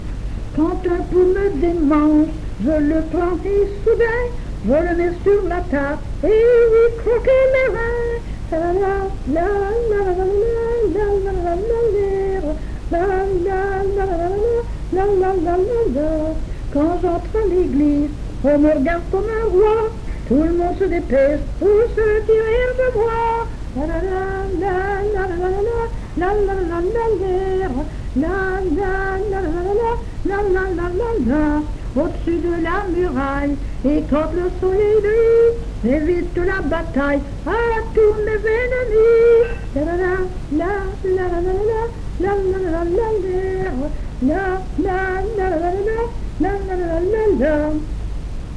Genre : chant
Type : chanson narrative ou de divertissement
Lieu d'enregistrement : Jolimont
Support : bande magnétique
Vieille chanson.